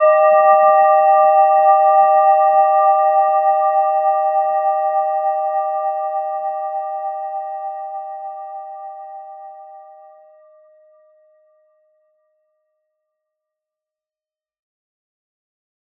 Gentle-Metallic-2-G5-mf.wav